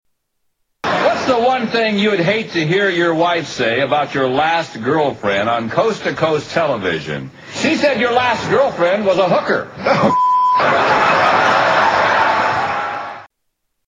Tags: Media Worst Game show answers in History Television Stupid Answers Game Shows